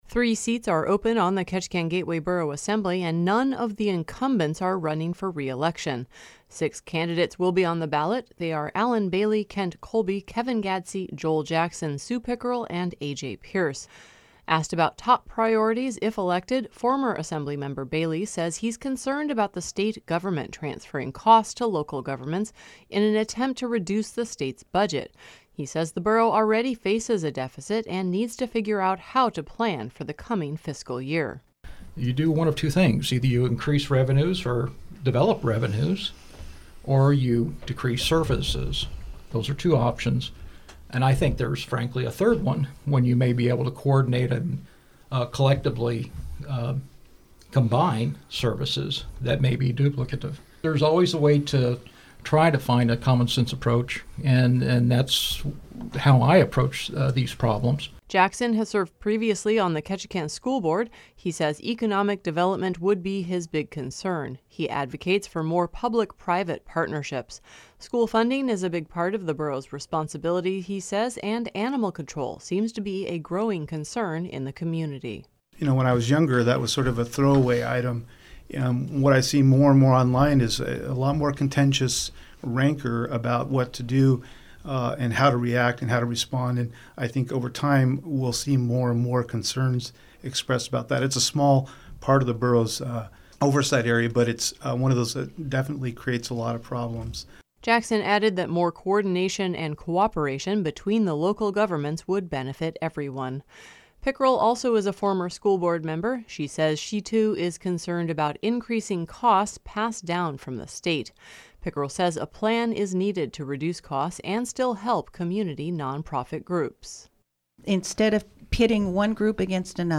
Six candidates for Ketchikan Gateway Borough Assembly participated in KRBD’s on-air candidate forum Wednesday. They talked about economic development, grants for nonprofits and how to plan for likely budget constraints.